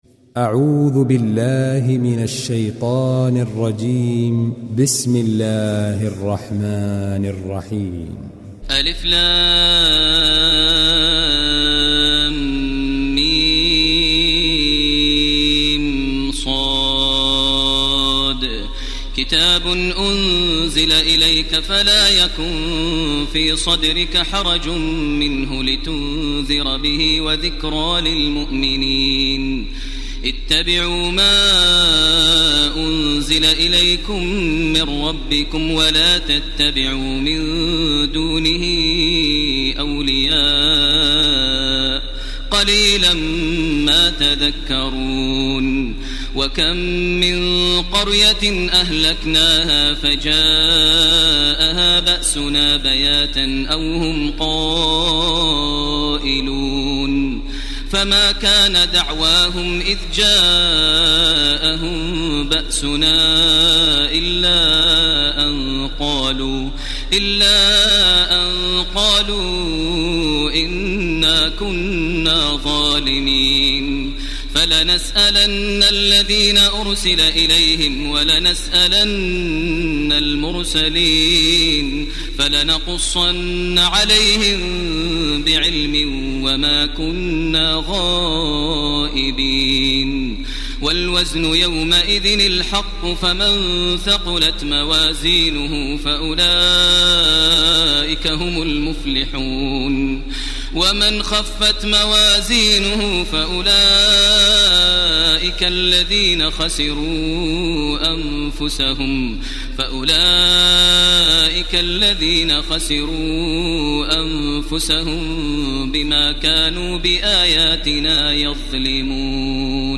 تراويح الليلة الثامنة رمضان 1430هـ من سورة الأعراف (1-84) Taraweeh 8 st night Ramadan 1430H from Surah Al-A’raf > تراويح الحرم المكي عام 1430 🕋 > التراويح - تلاوات الحرمين